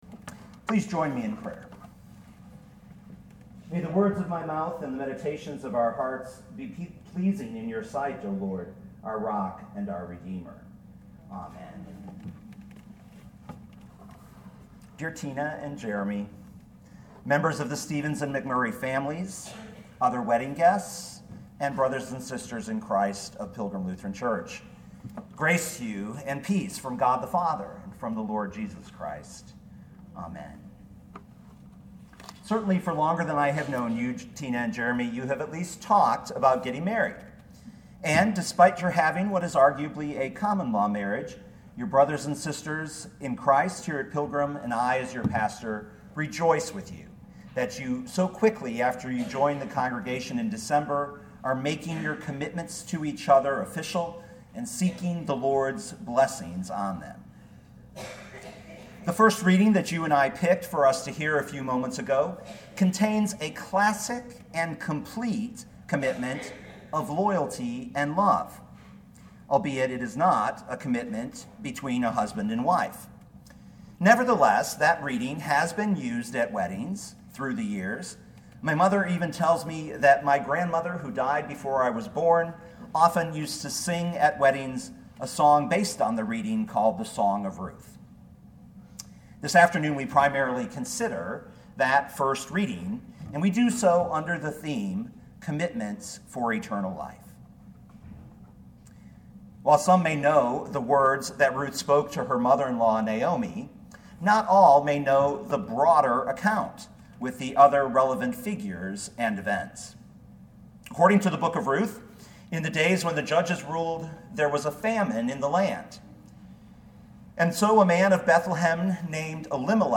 2018 Ruth 1:16-17 Listen to the sermon with the player below, or, download the audio.